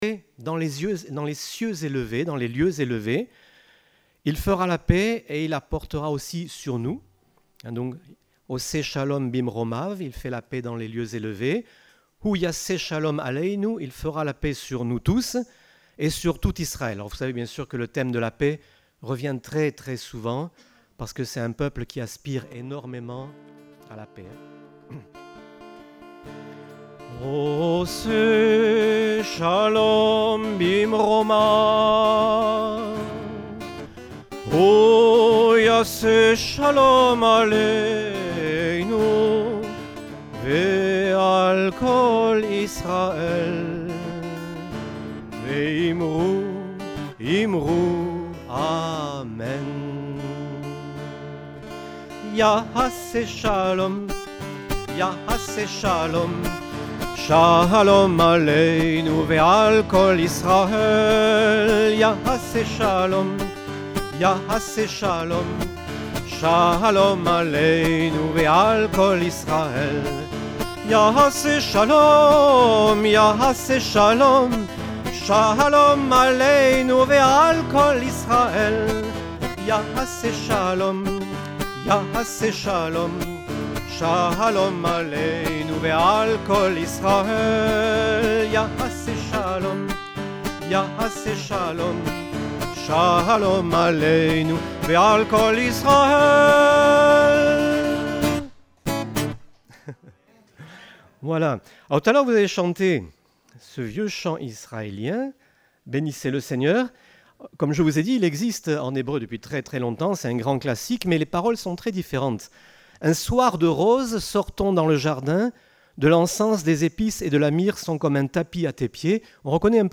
Type De Service: Culte Dominical